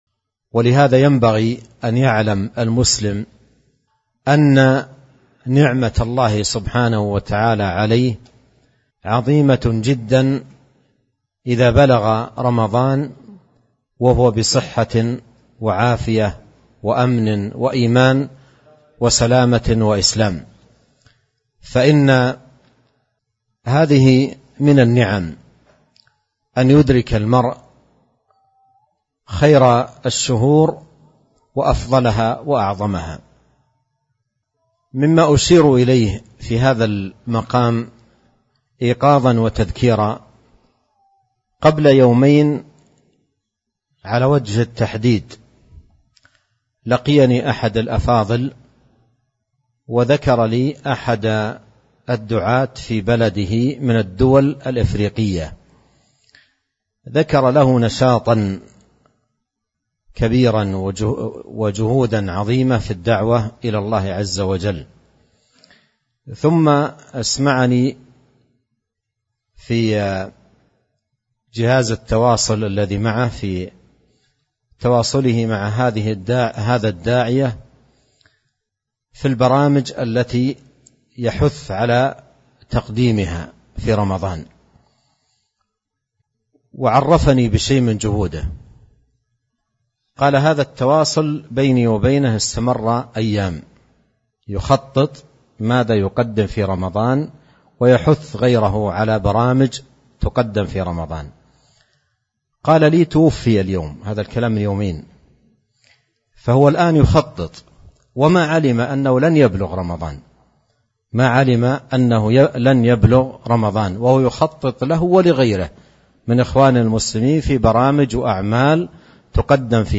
موعظة بليغة من النعم إدراك شهر رمضان